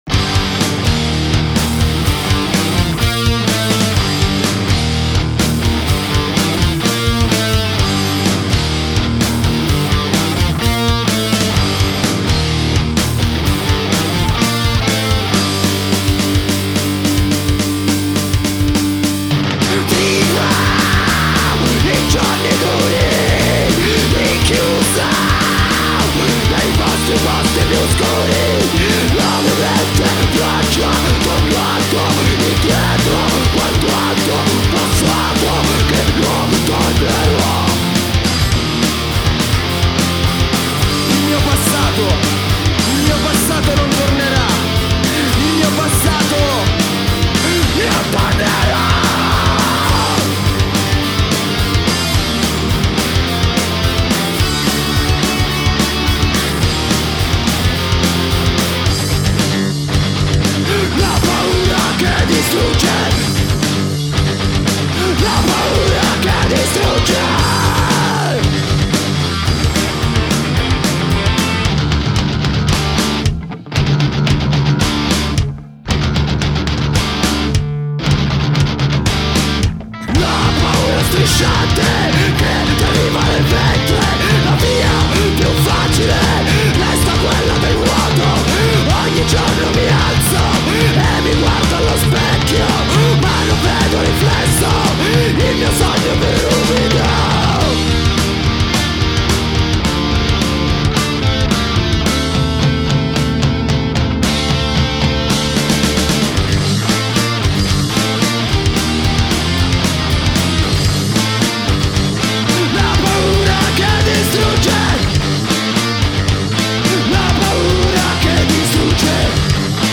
Genere: Hardcore/Punk
Batteria
Chitarra
Basso
Voce